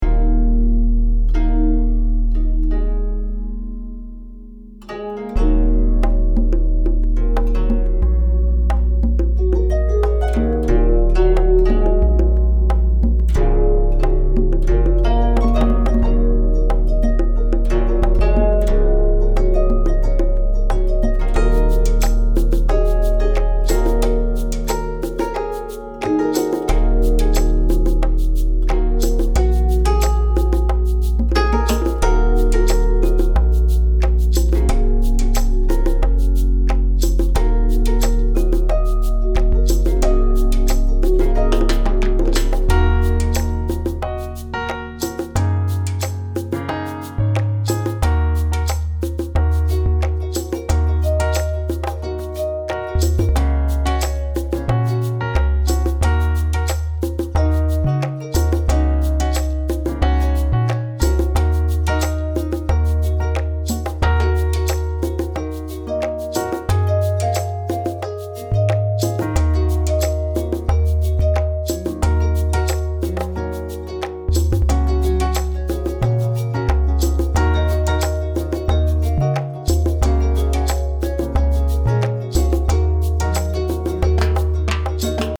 These original tracks have been created using real instruments, midi composition, and recorded sound.